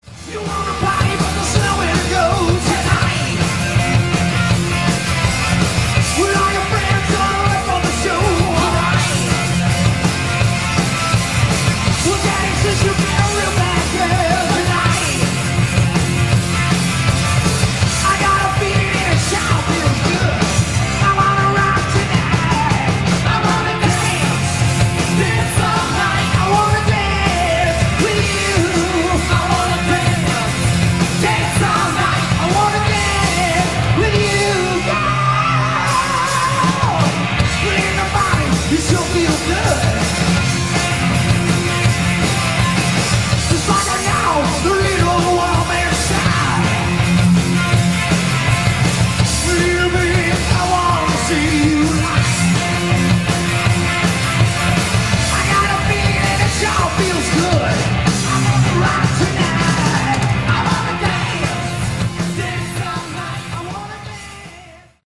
Category: Hard Rock
lead guitar, backing vocals
lead vocals
bass
drums
live